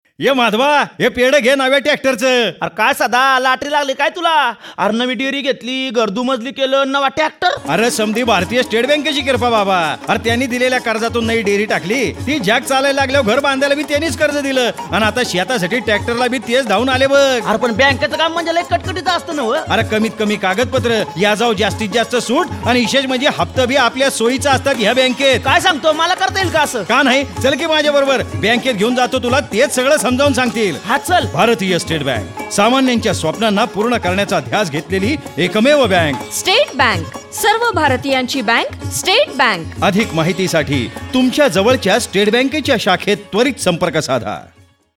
Tags: SBI Bank Vritti i-Media audio advertising rural advertising